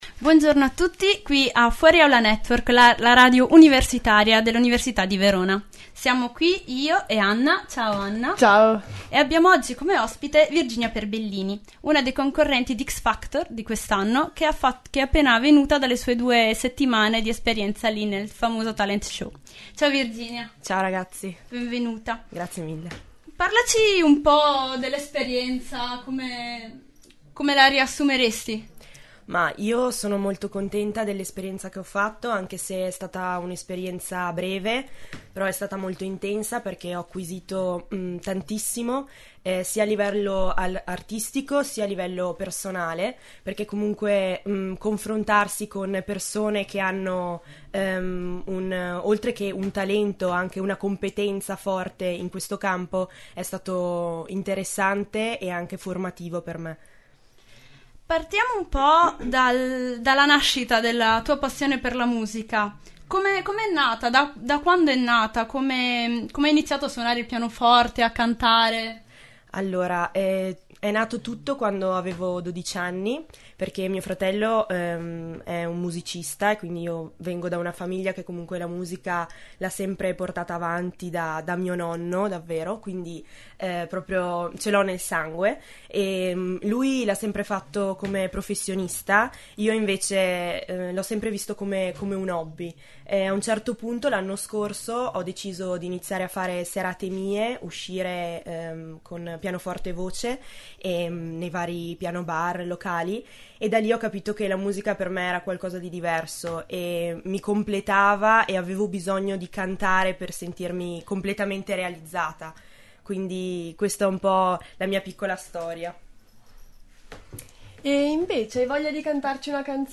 Nel podcast potrete addirittura ascoltare una sua cover cantata a cappella!